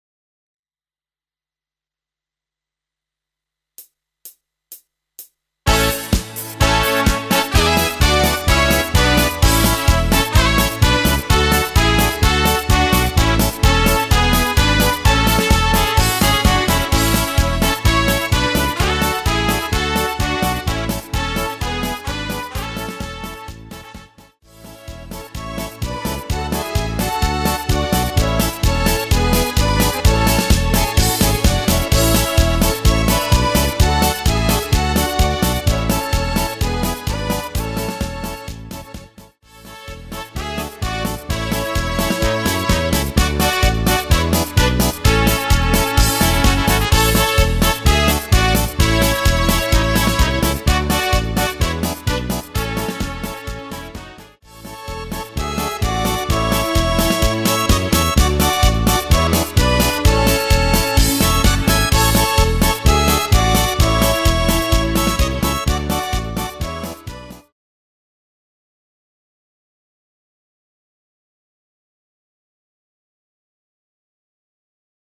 Rubrika: Národní, lidové, dechovka
- polka - směs